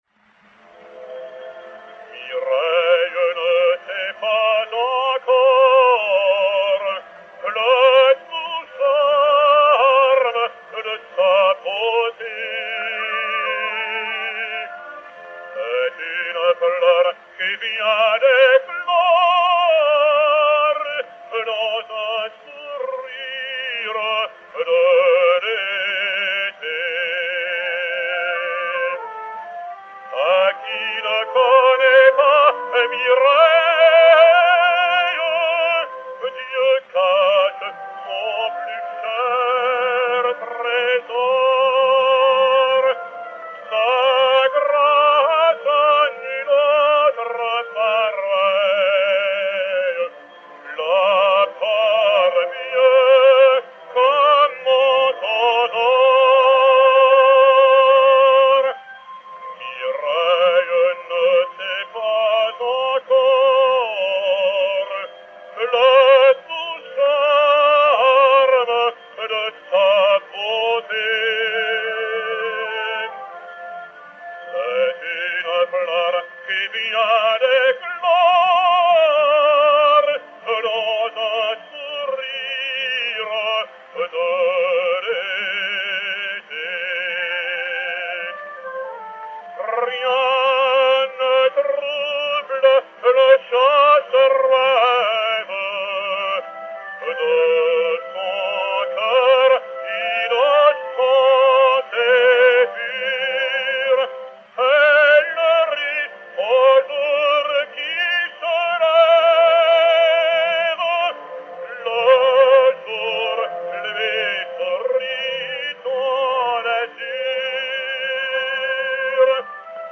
Jean Noté, baryton, avec orchestre